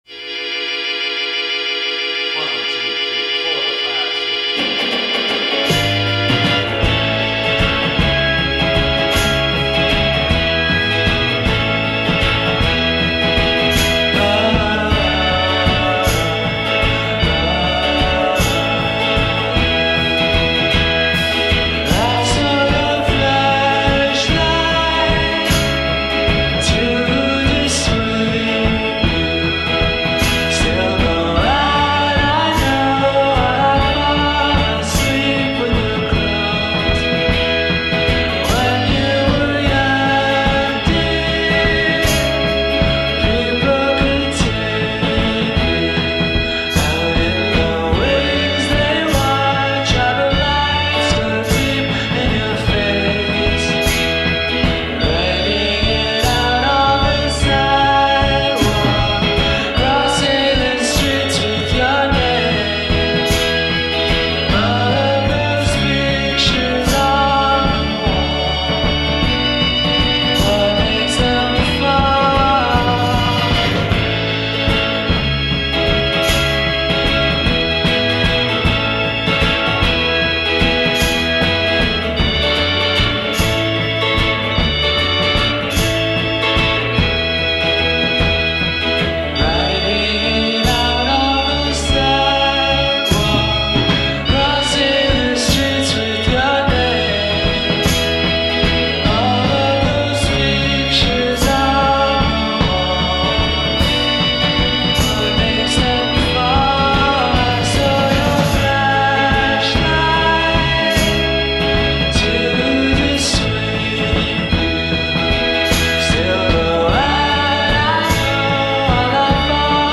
A sing-song lullaby
the song's lush, dreamy instrumentation recalls '60s pop
appropriately sweet singing